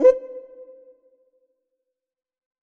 Index of /90_sSampleCDs/EdgeSounds - Drum Mashines VOL-1/M1 DRUMS
MHI CUICA.wav